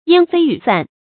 煙霏雨散 注音： ㄧㄢ ㄈㄟ ㄧㄩˇ ㄙㄢˋ 讀音讀法： 意思解釋： 形容眾多。